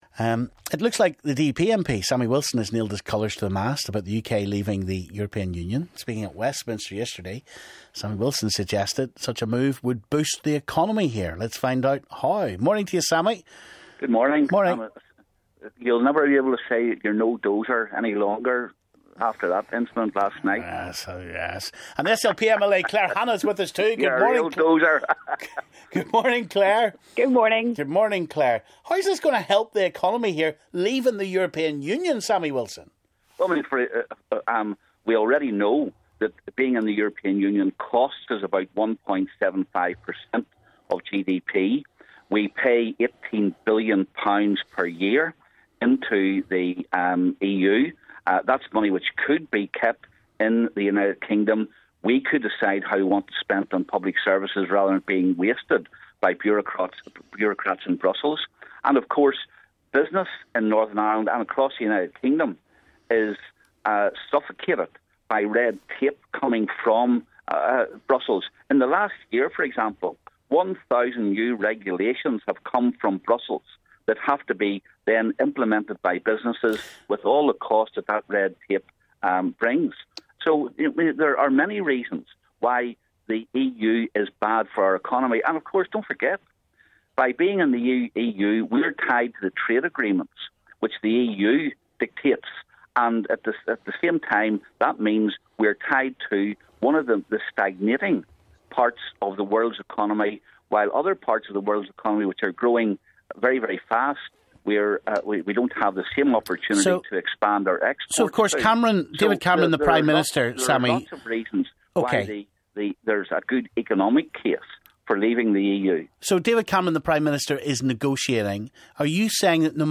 EU quit time? Sammy Wilson and Claire Hanna battle it out